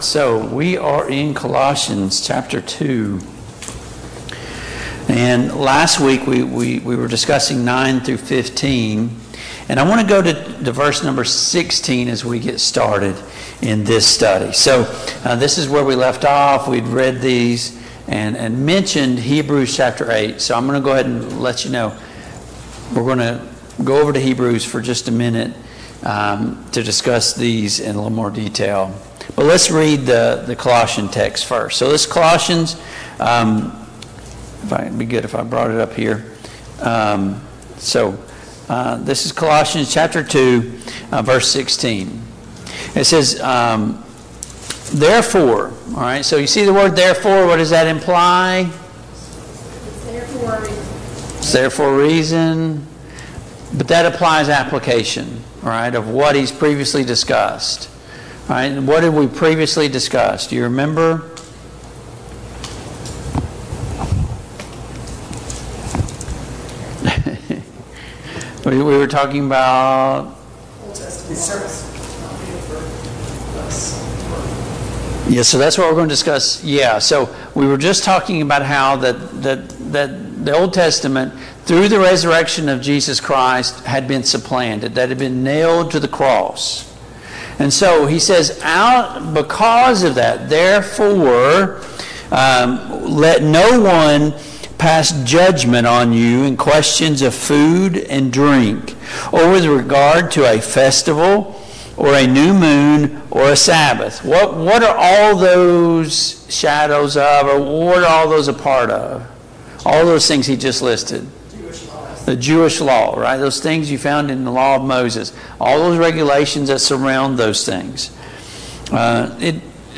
Passage: Colossians 2:16-23 Service Type: Mid-Week Bible Study